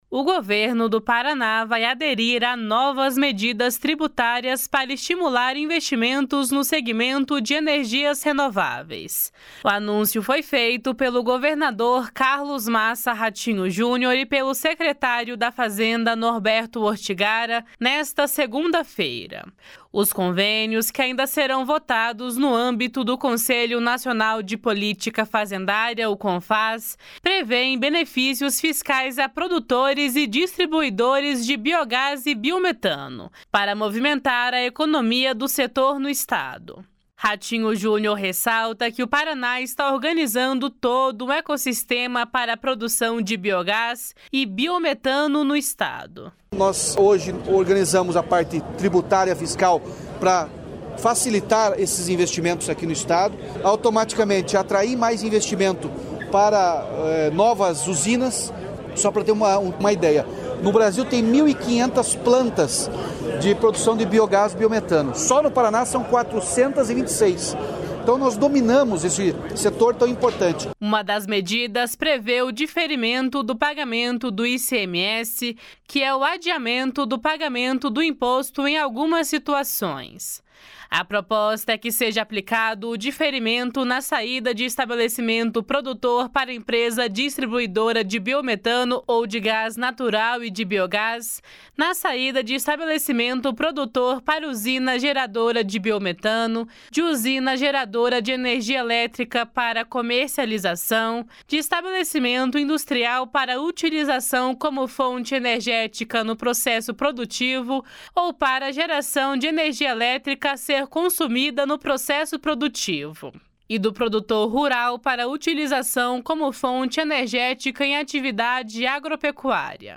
Ratinho Junior ressalta que o Paraná está organizando todo um ecossistema para a produção de biogás e biometano no Estado. // SONORA RATINHO JUNIOR //